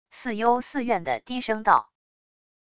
似幽似怨的低声道wav音频